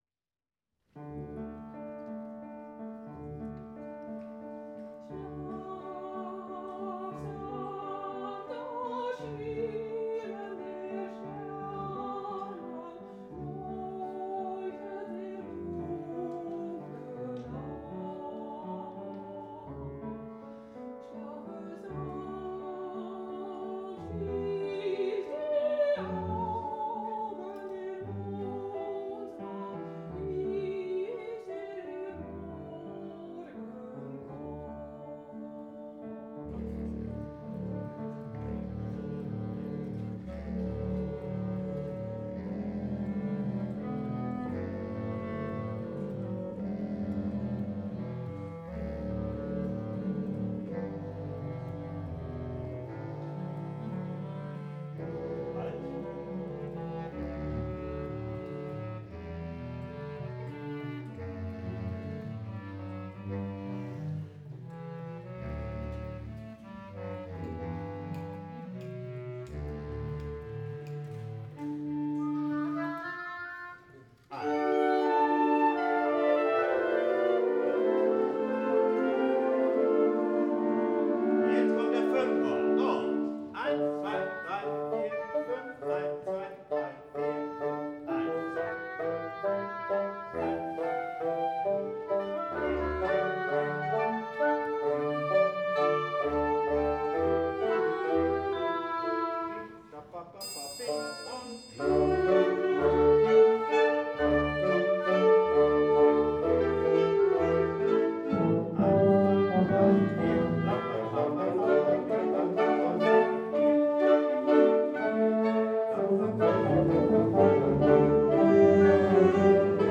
Music for Windorchestra - Anneloes Wolters | composer
Zerschmetterling, recording of first practice Musikakademie Marktoberdorf.